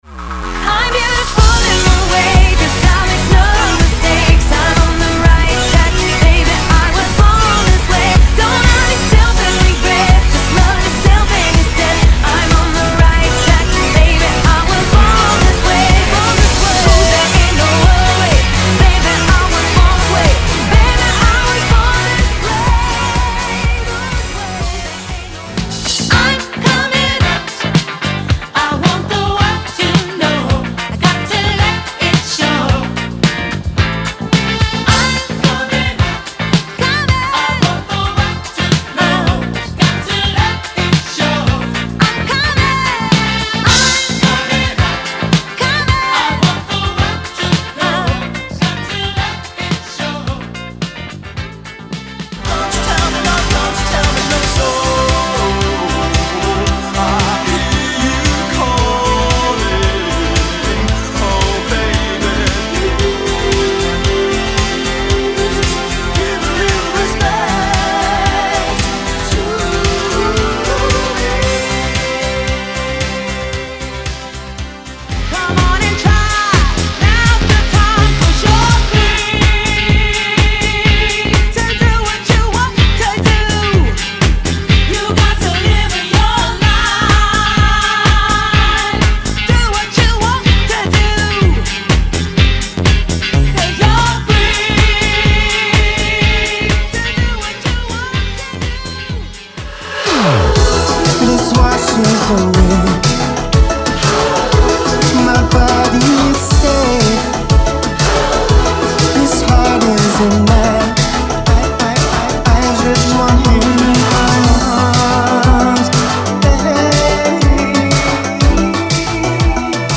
Description: Celebratory LGBTQIA+ anthems.